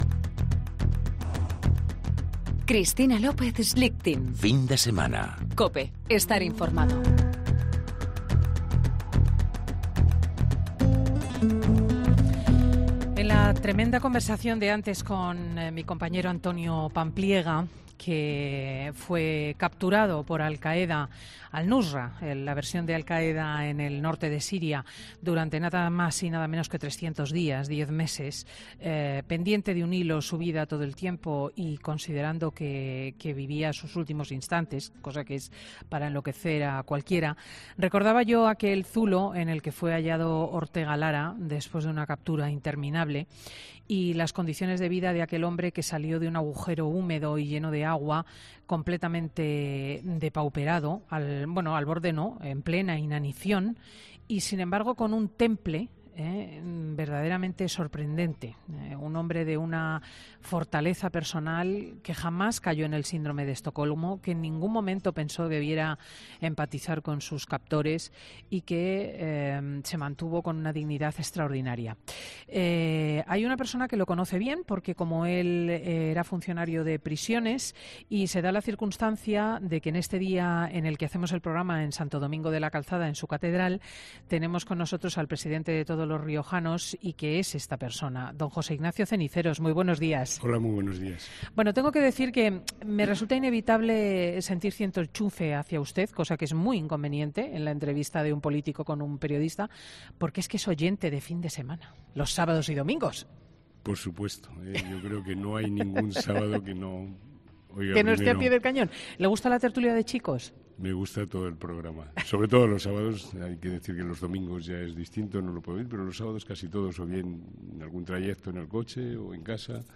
Escucha a José Ignacio Ceniceros, presidente de La Rioja
ESCUCHA LA ENTREVISTA COMPLETA | José Ignacio Ceniceros en 'Fin de semana' José Ignacio Ceniceros , ha recordado además que el próximo día 9 se celebra el Día de La Rioja, que conmemora la firma de su Estatuto de Autonomía.